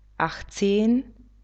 achtzehn [acht ce:n]